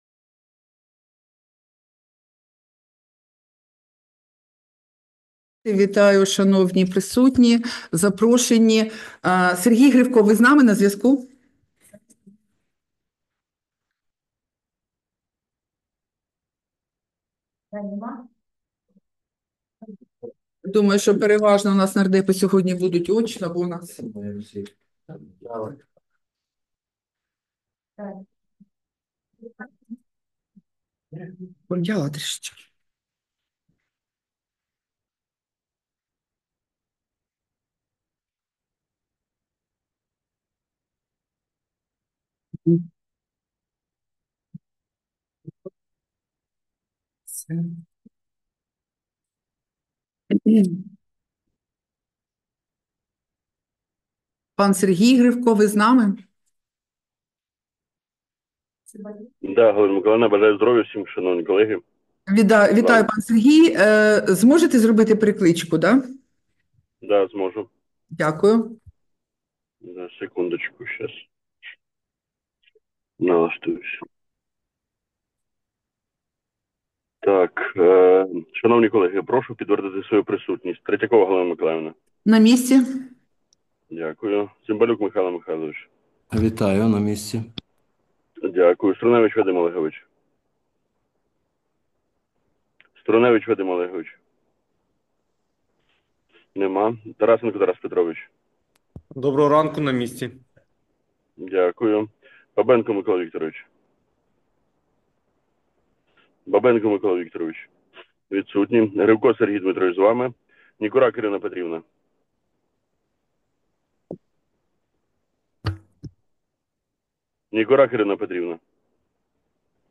Засідання Комітету від 8 травня 2025 року